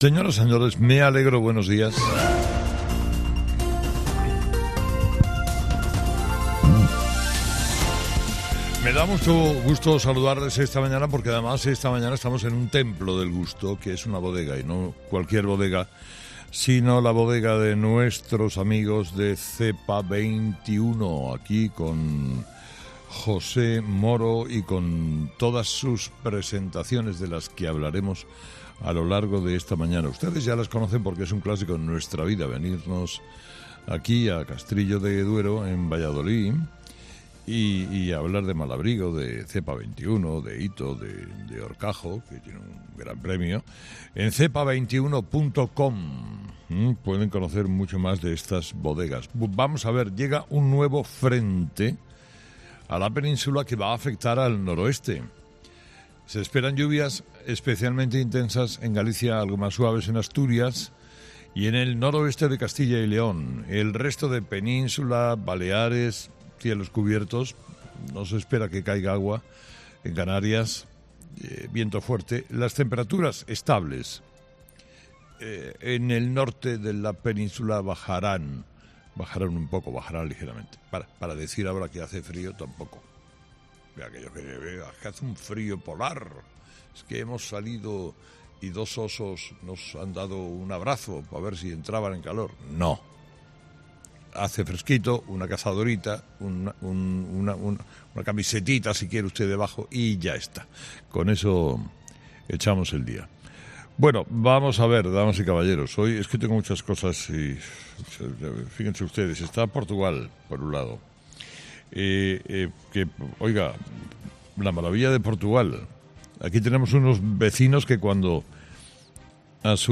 Escucha el análisis de Carlos Herrera a las 06:00 en Herrera en COPE del miércoles 8 de noviembre